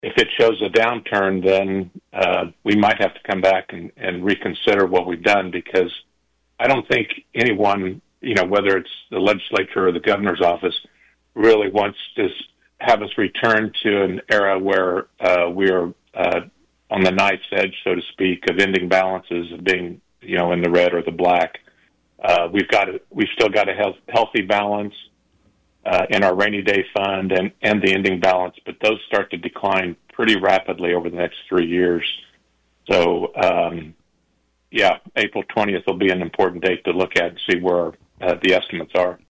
Schreiber and Argabright offered their comments during separate interviews on KVOE’s Morning Show Wednesday where another major talking point focused on the passage of Senate Bill 269, known as the “income tax trigger bill,” which aims to reduce individual and corporate income tax rates to as low as 4 percent.